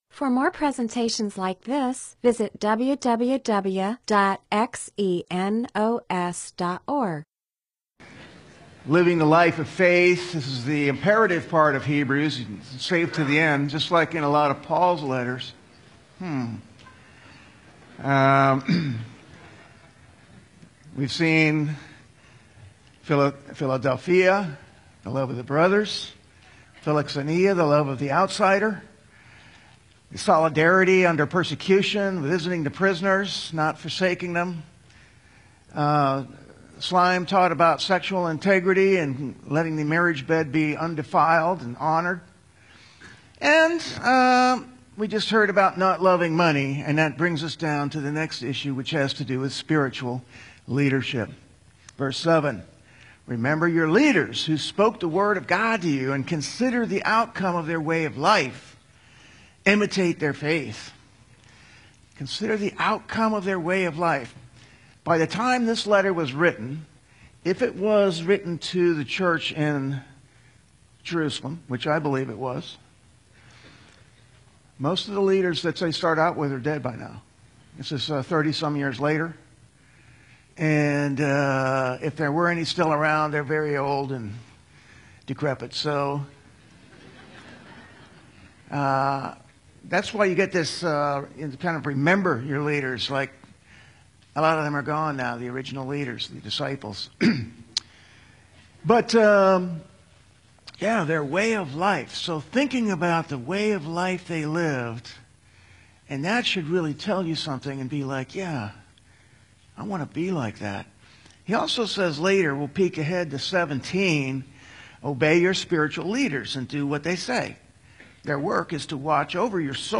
MP4/M4A audio recording of a Bible teaching/sermon/presentation about Hebrews 13:7; Hebrews 13:17; Mark 10:42-45; Ephesians 4:11-12.